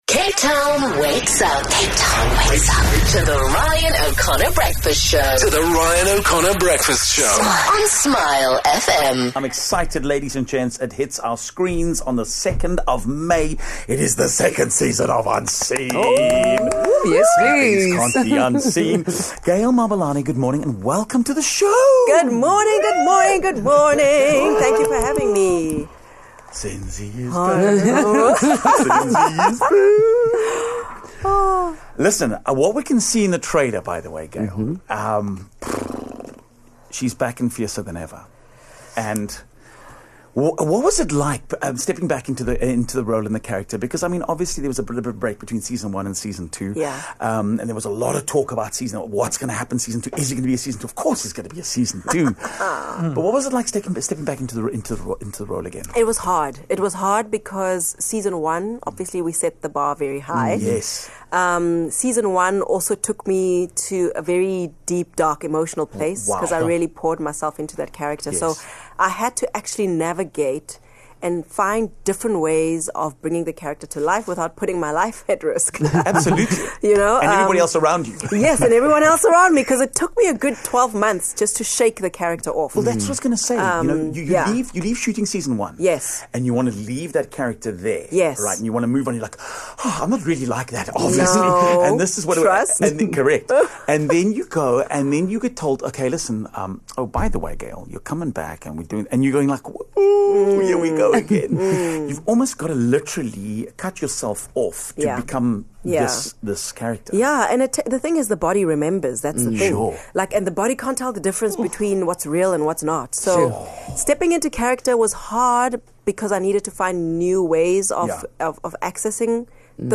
After a smashingly successful first season, acclaimed South African crime thriller Unseen is set for a second season next month. Gail Mabalane who stars as Zenzi Mwale joined us to talk about what fans can expect in the 6 part 2nd season.